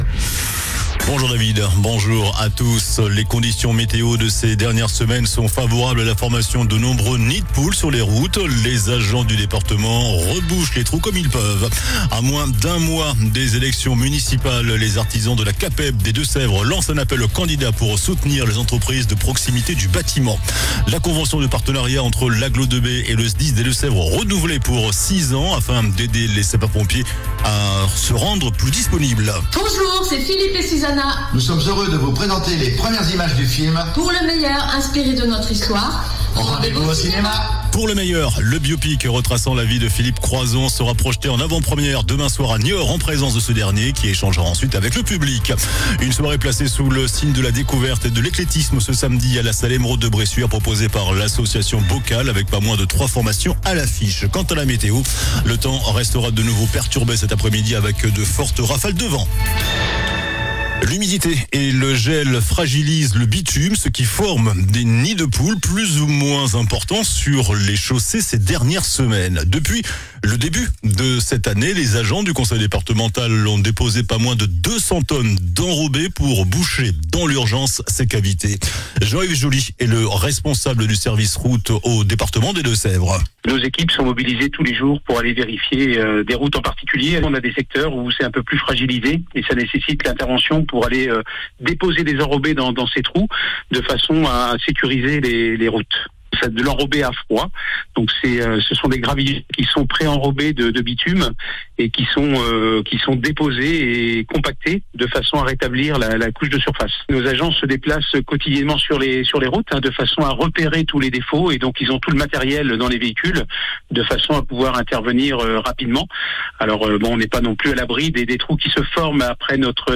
JOURNAL DU JEUDI 19 FEVRIER ( MIDI )